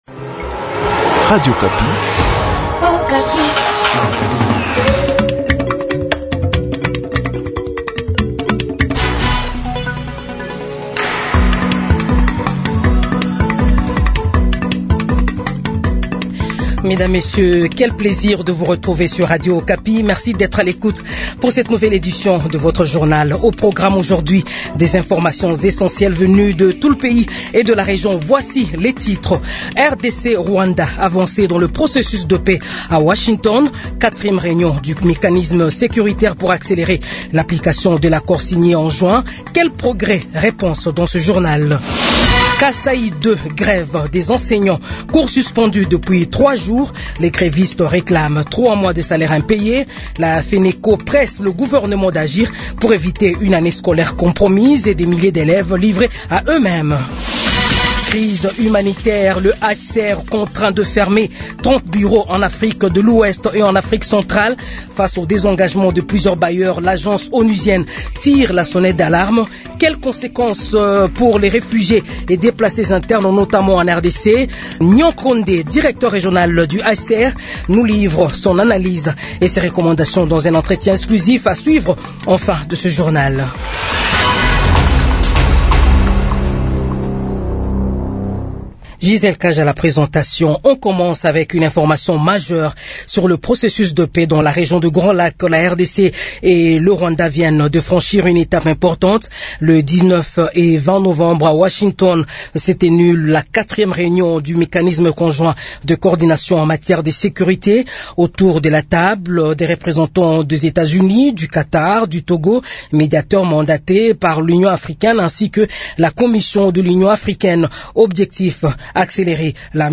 Jounal 15h